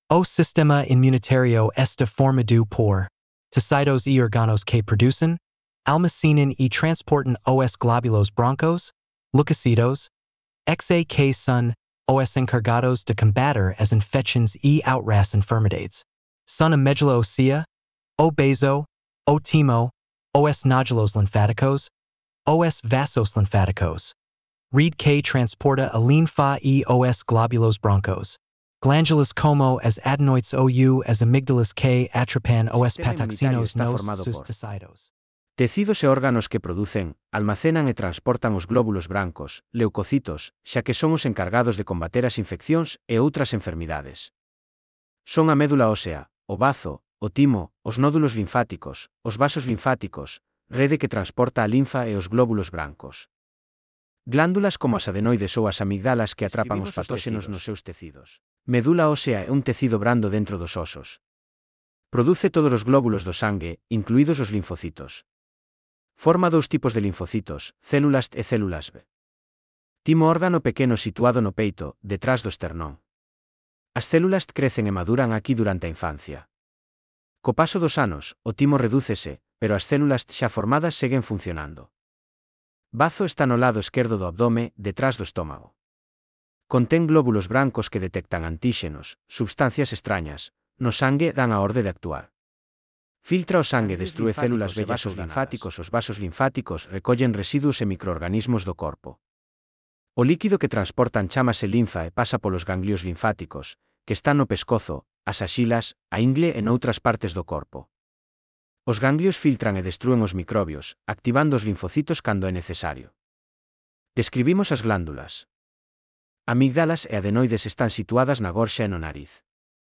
Elaboración propia coa ferramenta Narakeet (CC BY-SA)